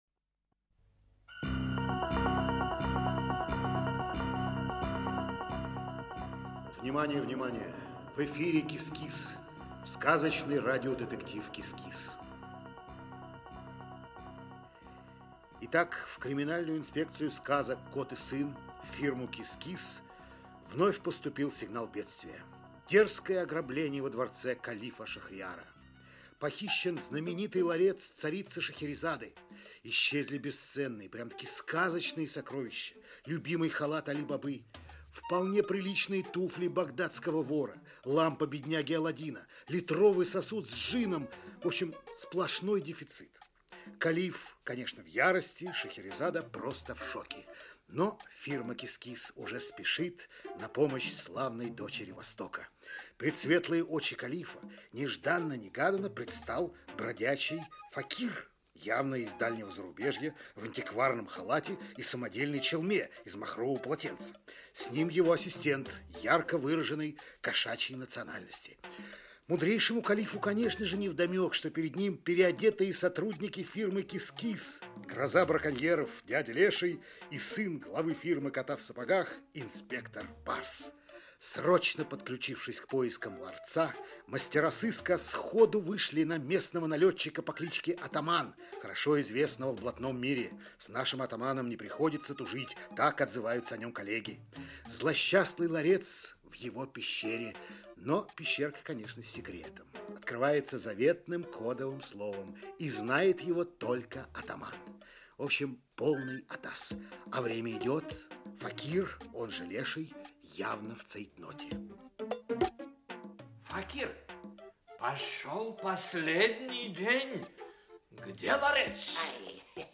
Аудиокнига КИС-КИС. Дело № 4. "Ларец Шахерезады". Часть 4 | Библиотека аудиокниг